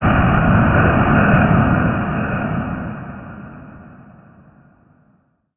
Cri de Tortank Gigamax dans Pokémon HOME.
Cri_0009_Gigamax_HOME.ogg